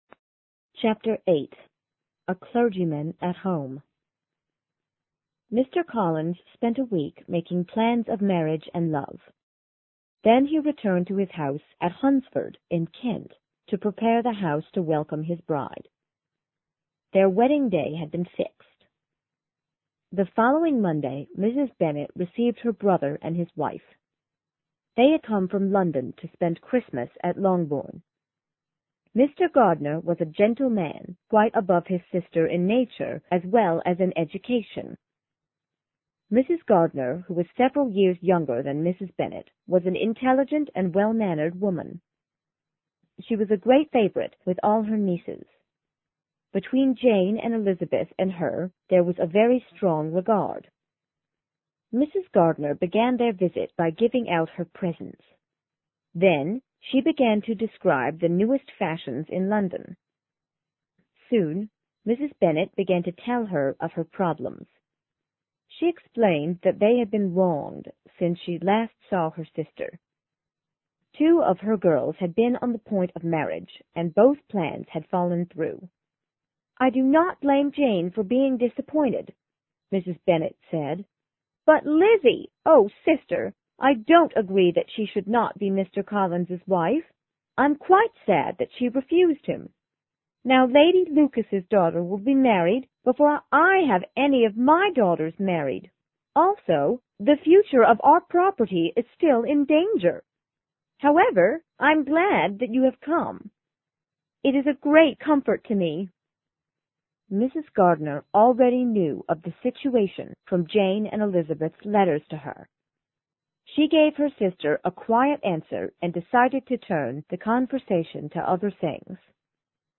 有声名著之傲慢与偏见 Chapter8 听力文件下载—在线英语听力室